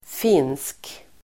Uttal: [fin:sk]